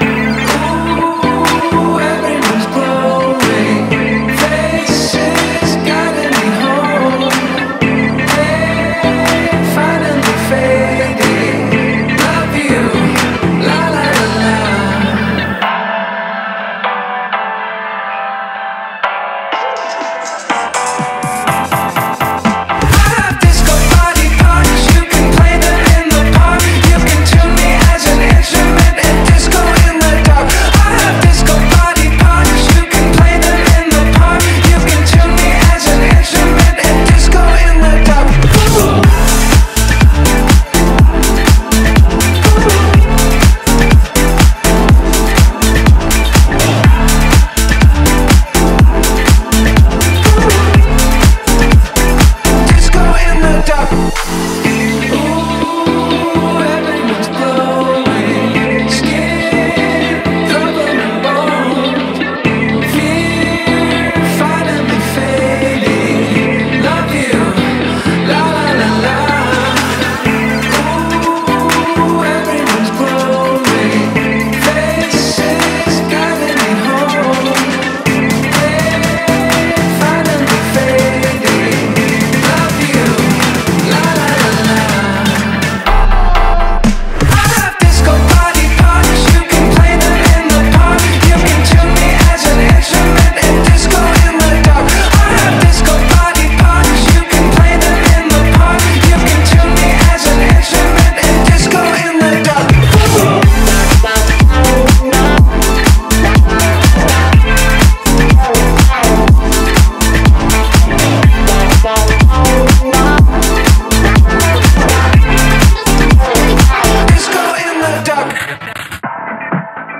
BPM123
Audio QualityPerfect (High Quality)
コメント[FUTURE FUNK]